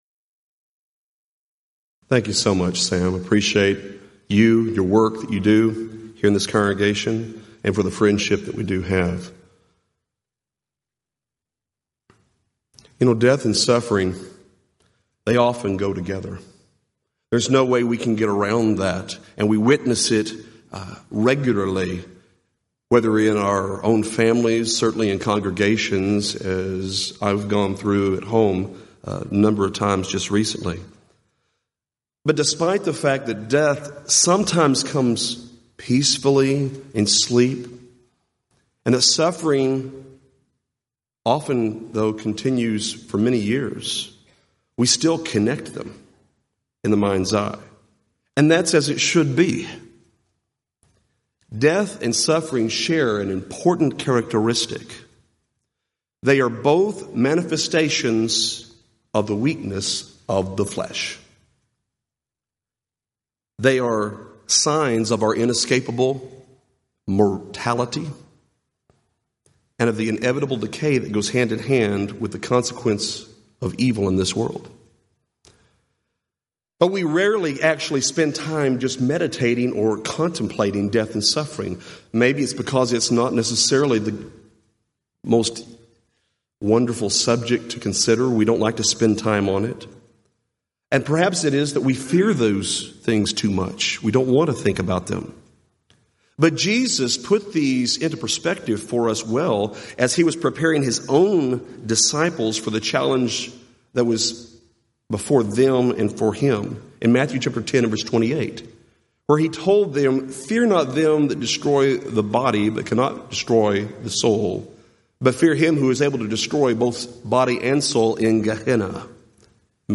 Event: 34th Annual Southwest Lectures
lecture